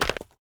Chopping and Mining
mine 5.wav